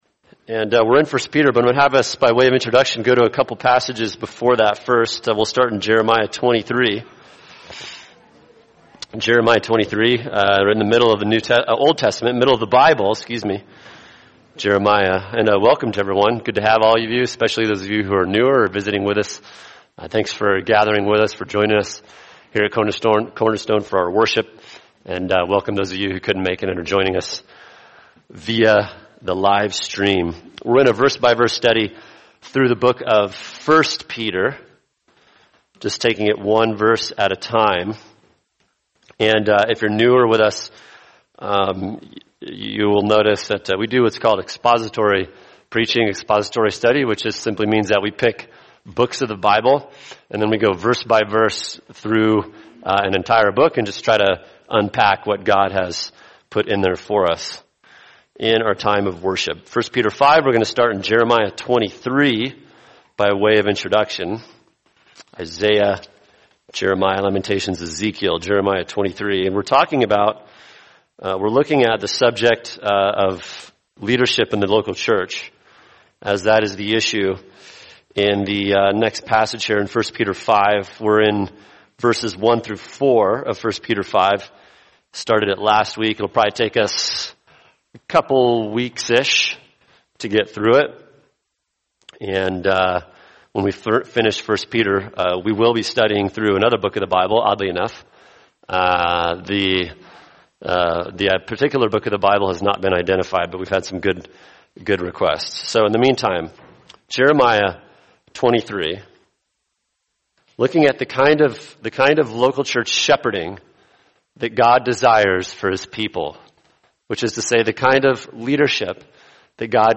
[sermon] 1 Peter 5:1-4 The Kind of Shepherding God Wants For Us – Part 2 | Cornerstone Church - Jackson Hole